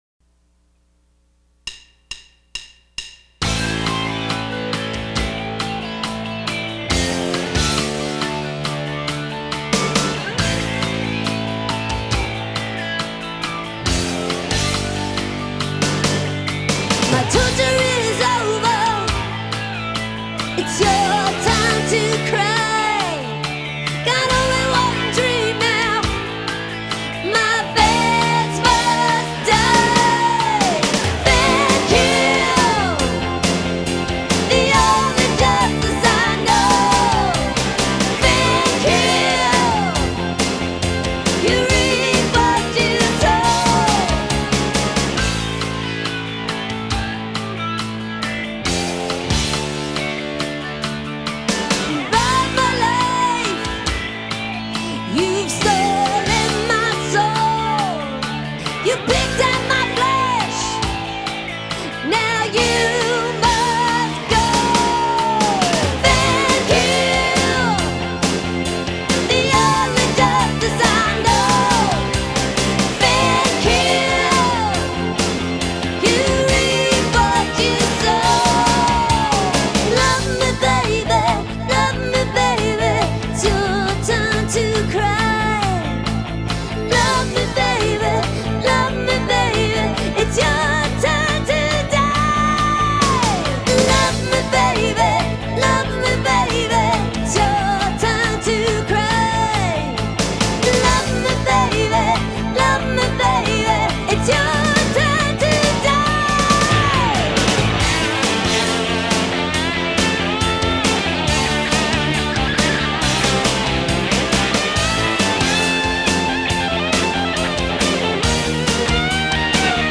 popular rock song